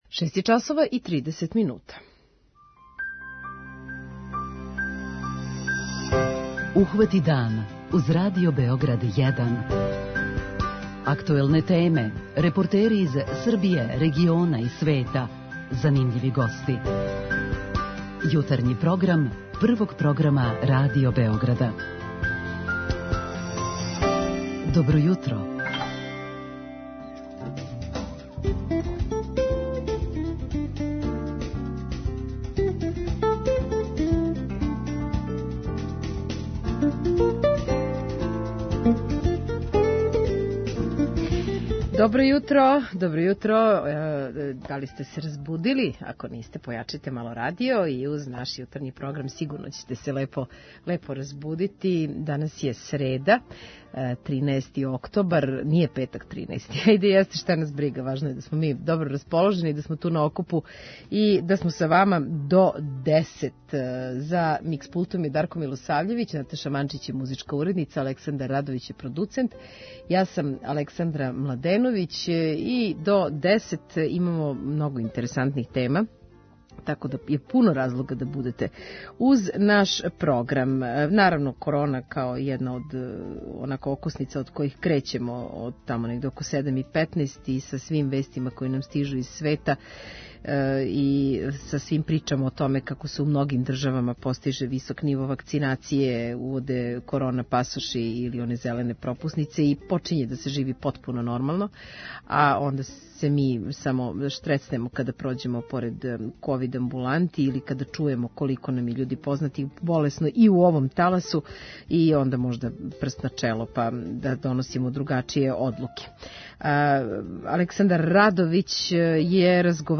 Јутарњи програм Радио Београда 1!